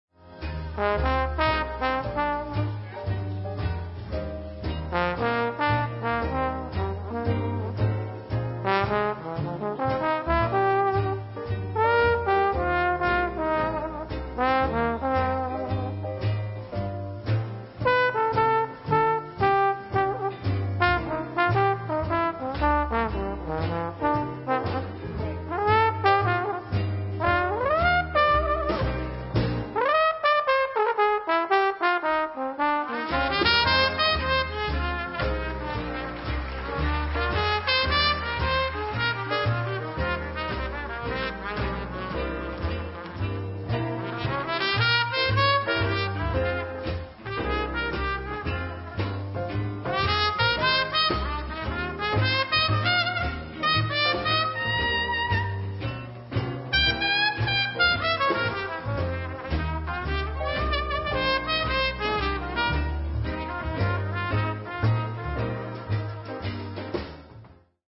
Recorded Live at NEWORLEANS JAZZ ASCONA il 5 luglio, 2001